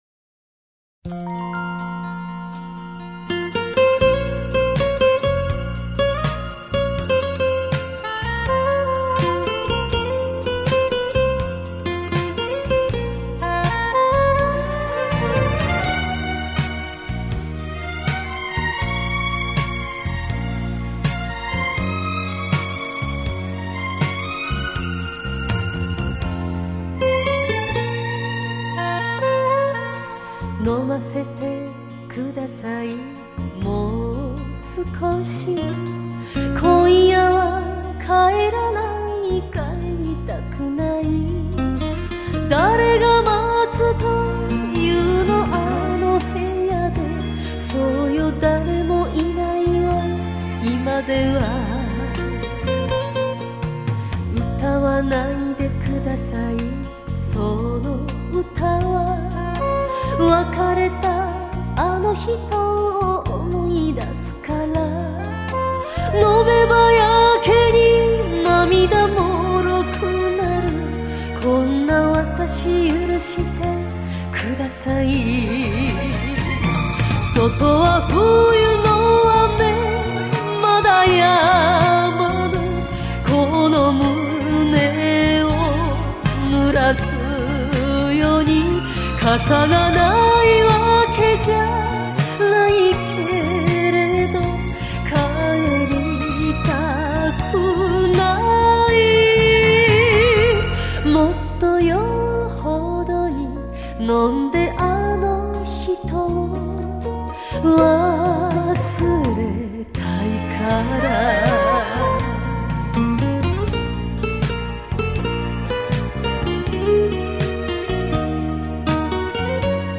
原唱版